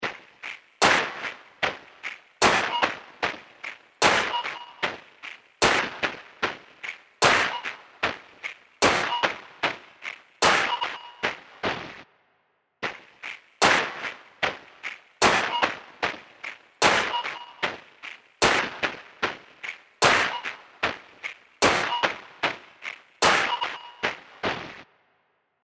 Connect (Drum Sample).mp3